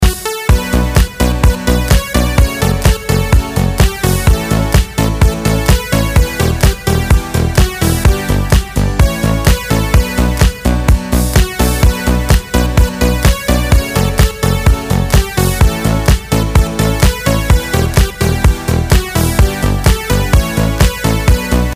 زنگ موبایل ملایم